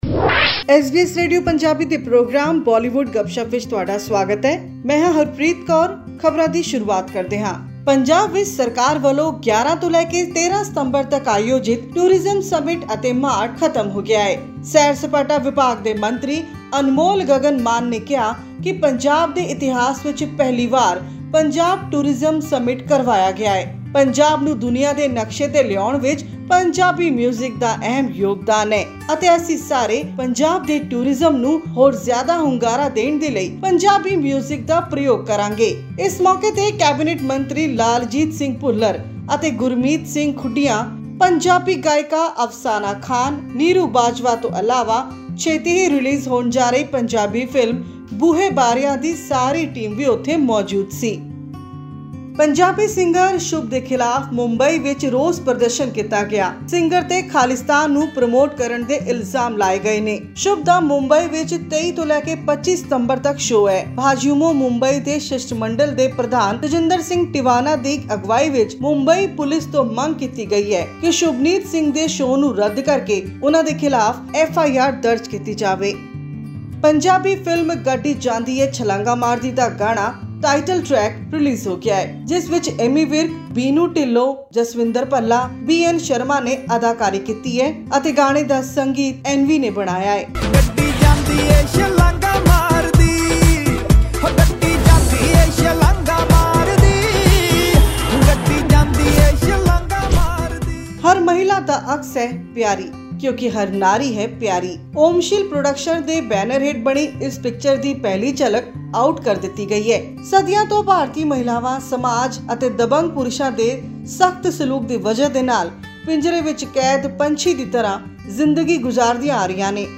This and more in our weekly news segment of Bollywood Gupshup on upcoming movies and songs.